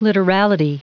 Prononciation du mot literality en anglais (fichier audio)
Prononciation du mot : literality